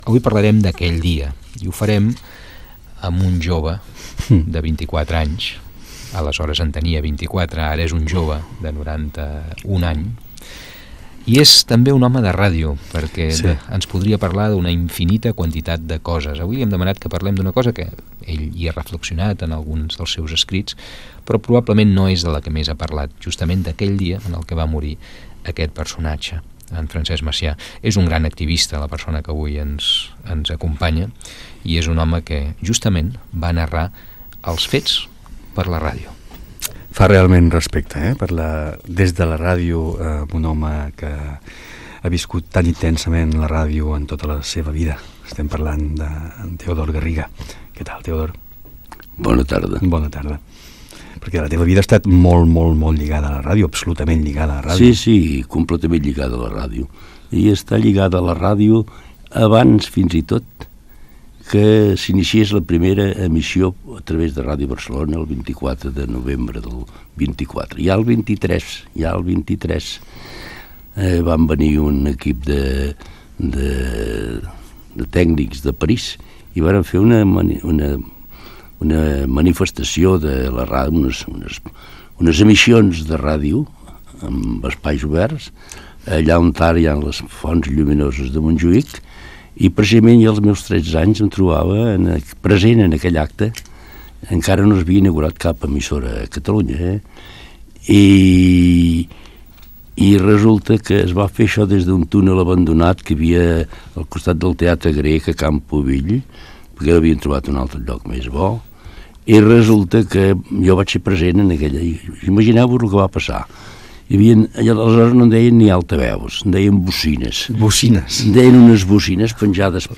Fragment d'una entrevista
Gènere radiofònic Entreteniment